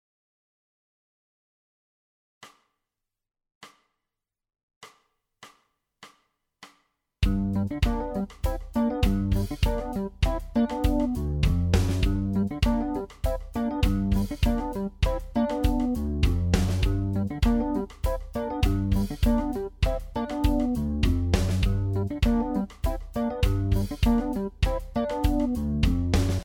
Weitere Hörbeispiele „Funk ´n` Soul Riffs
Funk `n` Soul Riff 6